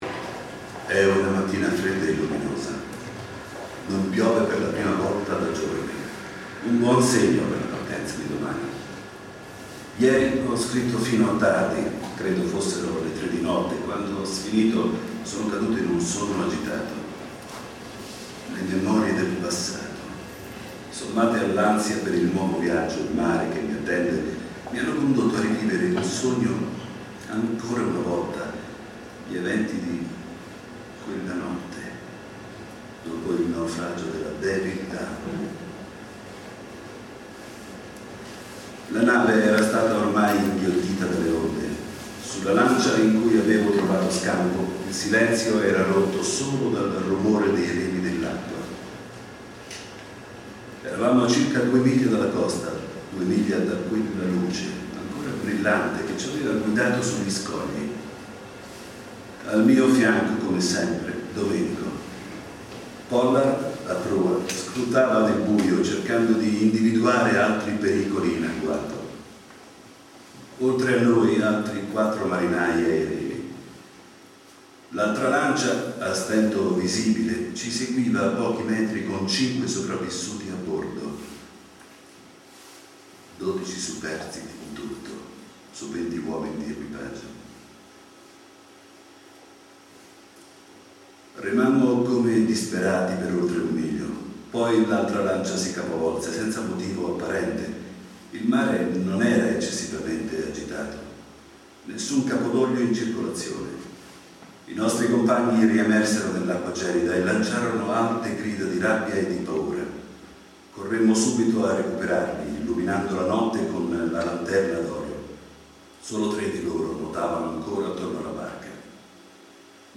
Letture di brani scelti